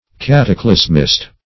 Search Result for " cataclysmist" : The Collaborative International Dictionary of English v.0.48: Cataclysmist \Cat`a*clys"mist\, n. One who believes that the most important geological phenomena have been produced by cataclysms.